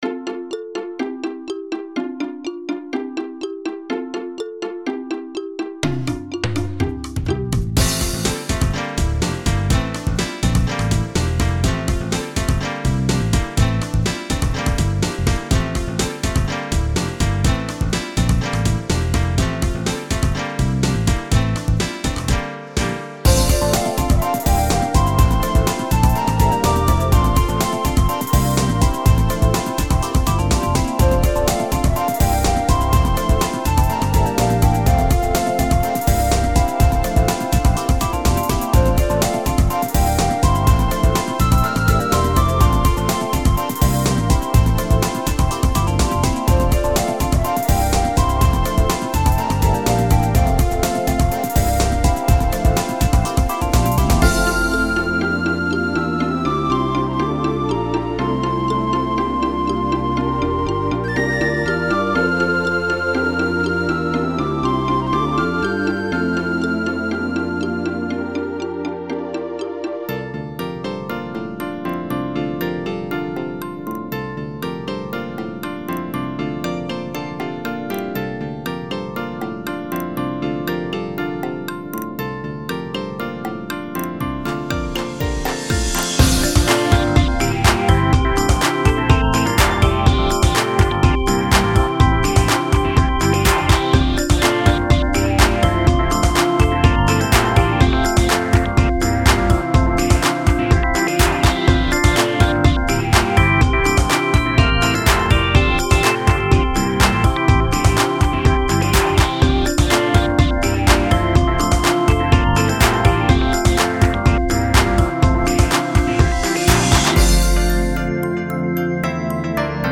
Audio QualityPerfect (High Quality)
87-124 bpm